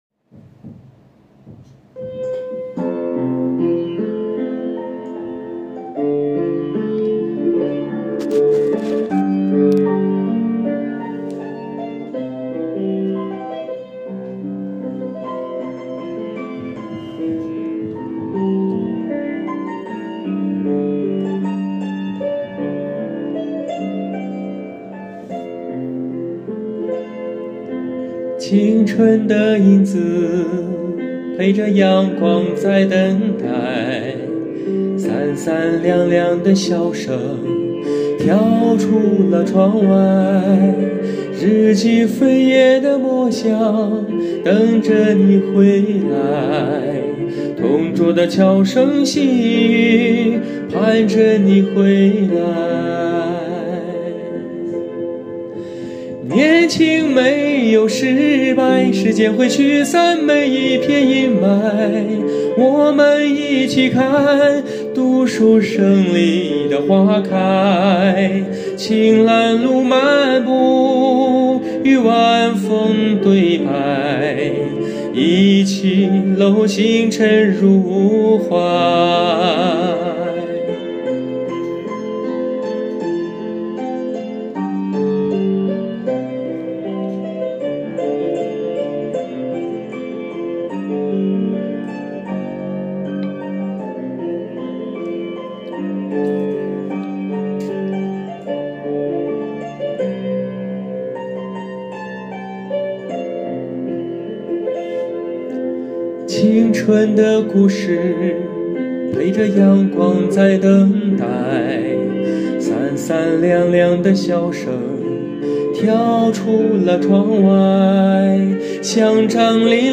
创作型抒情男高音、青年导演。
在旋律创作方面，采用的是校园民谣式手法进行，旋律流畅，郎朗上口，这既符合校园青春气息，又有美好回忆的感觉，整首歌调子定为降E调，音域不宽，很容易为大众所接受。副歌采用的是直抒胸臆，直接抒发情感，也表达了年轻人要勇敢去冲，不怕失败的精神内涵。